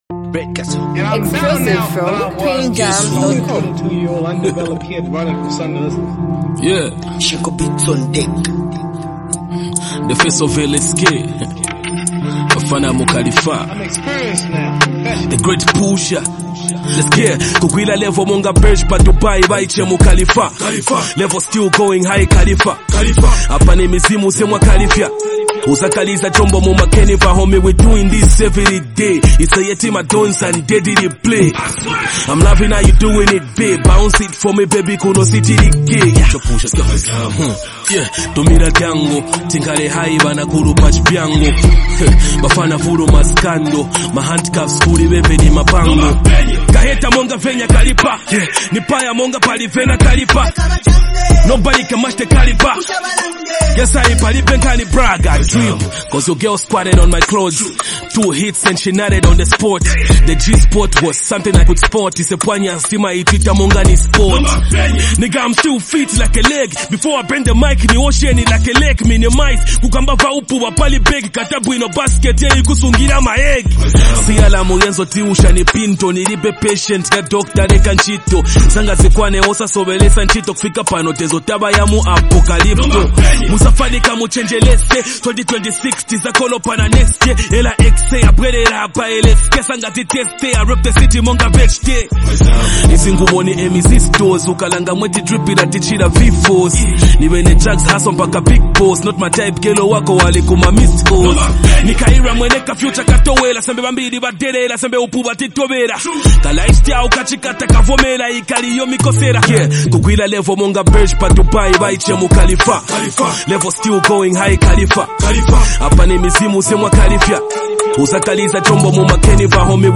vibrant and uplifting song